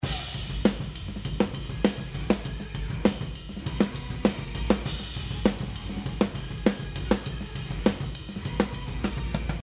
本ライブセッションのレコーディングでは、ドラムのトップにLewittの革命的なマイク、LCT640TSが2本使用されています。
今回の場合、恐らくは「ライブレコーディング」ということもあり、かなり近めにトップマイクは設定されているようです。
コンプレッサーでも追いつかないほどの「立ち上がり」部分のトランジェントと、リリース部分を自在にコントロールするSmackを使い、アタックを少し鈍らせ、さらにリリース部分も適度にぼかしたい、というセッティングがこちらです。
M03_Lewitt-Mix-Contest_SmackATK.mp3